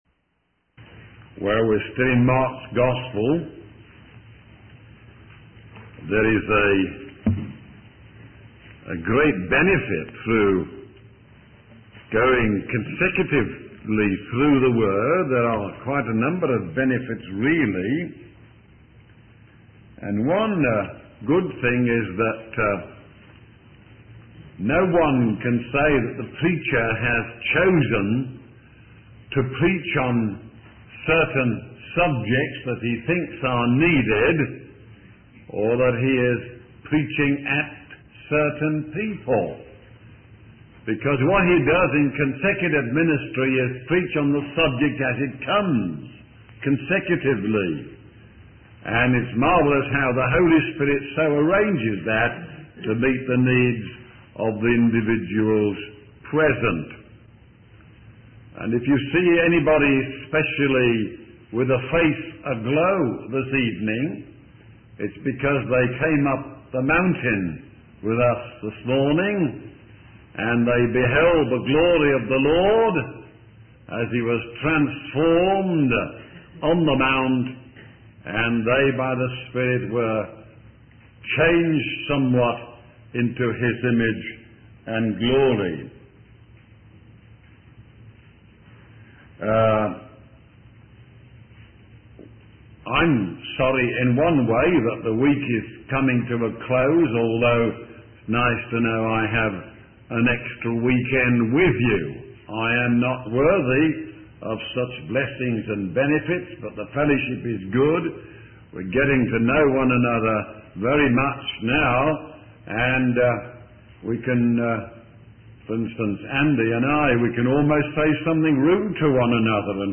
In this sermon, the speaker begins by reading a passage from the book of Mark about a man who planted a vineyard and let it out to farm workers.